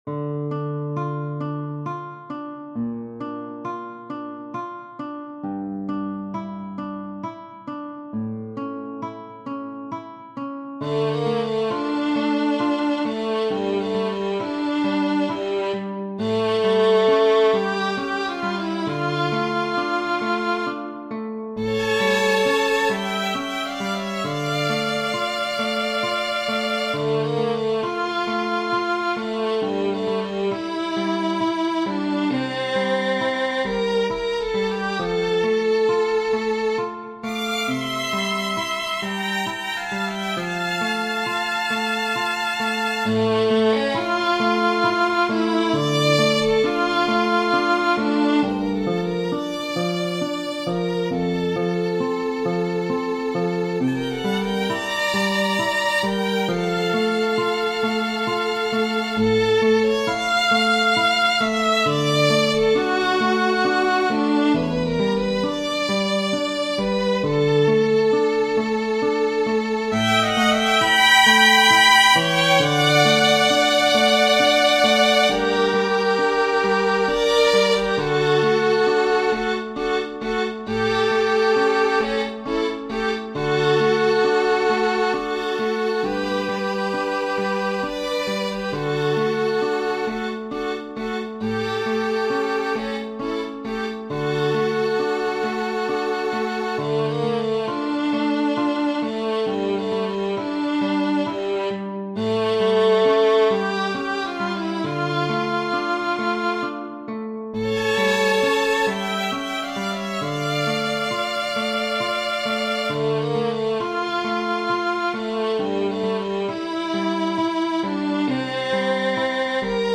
Schubert, F. Genere: Classica German text: Ludwig Rellstab Leise flehen meine Lieder Durch die Nacht zu Dir; In den stillen Hain hernieder, Liebchen, komm’ zu mir!